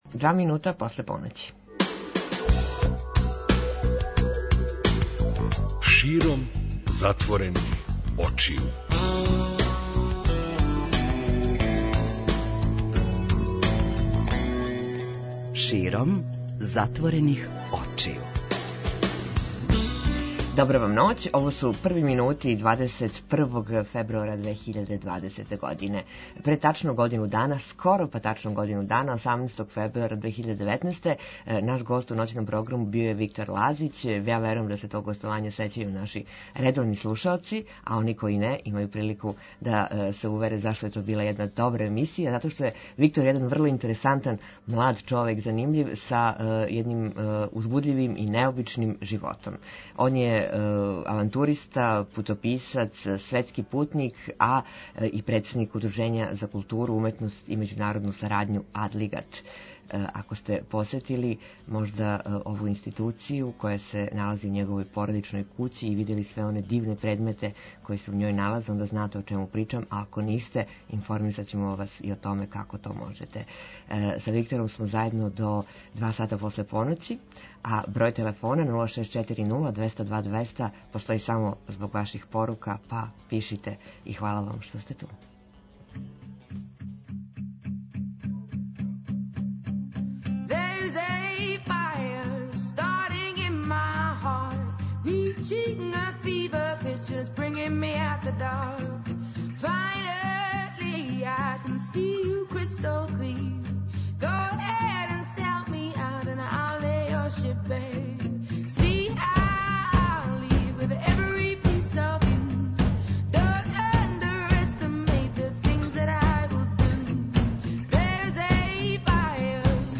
Гост